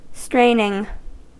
Ääntäminen
Ääntäminen US Haettu sana löytyi näillä lähdekielillä: englanti Käännös 1. pinnistely Straining on sanan strain partisiipin preesens.